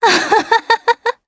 Isolet-Vox_Happy3_jp.wav